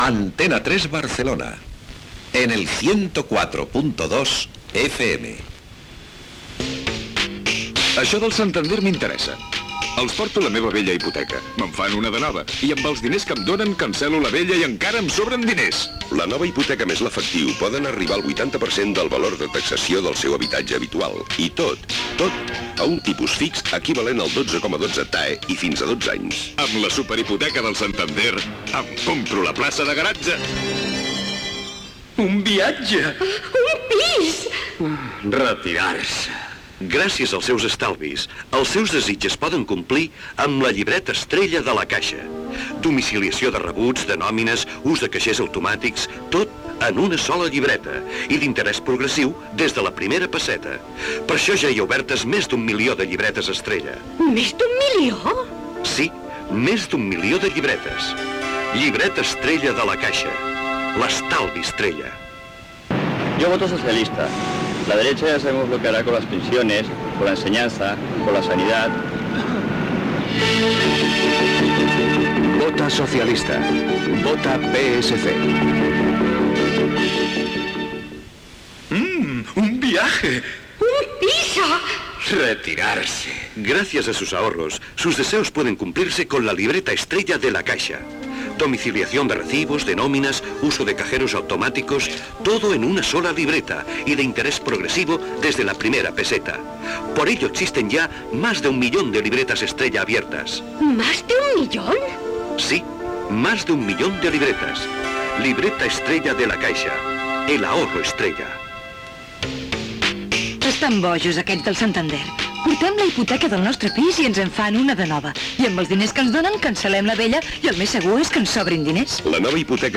Identificació de l'emissora, publicitat, indicatiu, tema musical, sintonia, horàries, identificació, careta del programa, informació dels partits de futbol masculí de la jornada
Esportiu
FM